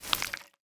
Minecraft Version Minecraft Version 25w18a Latest Release | Latest Snapshot 25w18a / assets / minecraft / sounds / mob / bogged / ambient4.ogg Compare With Compare With Latest Release | Latest Snapshot